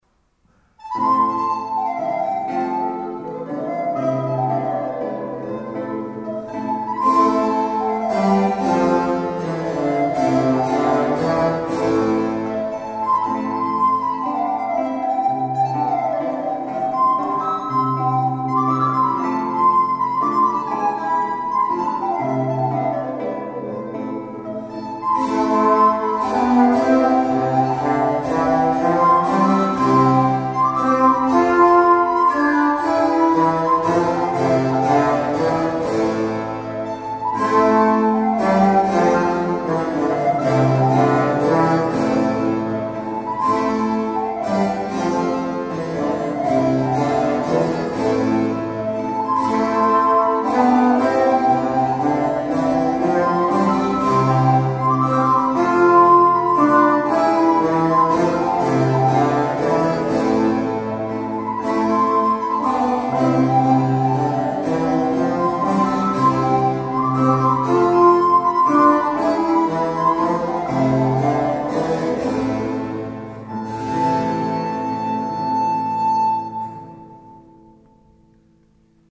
la registrazione è stata effettuata nel Maggio del 2011 nell'Oratorio dell’Annunziata di Piana Crixia (Savona).
Sono state utilizzate esclusivamente copie di strumenti rinascimentali.
L’eco è solo quella naturale dell'oratorio,